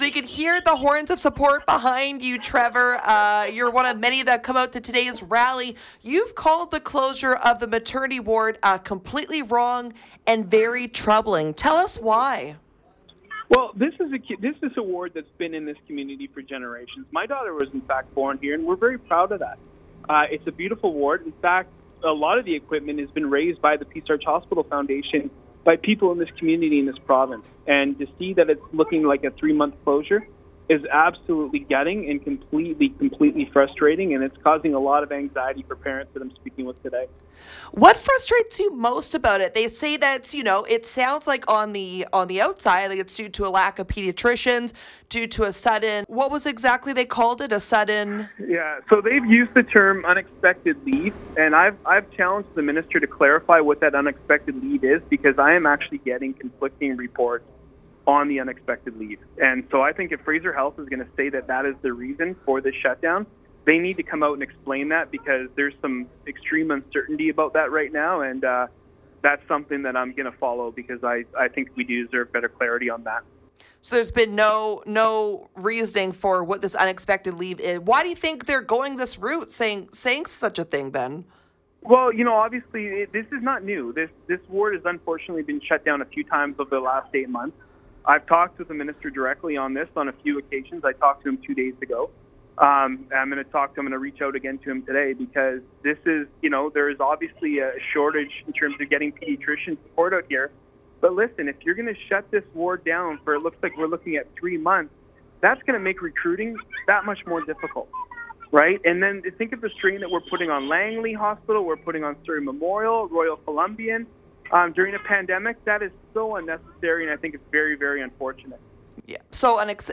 surrey-mla-trevor-halford-full-interview.mp3